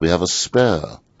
gutterball-3/Gutterball 3/Commentators/Jensen/jen_wehaveaspare.wav at 58b02fa2507e2148bfc533fad7df1f1630ef9d9b
jen_wehaveaspare.wav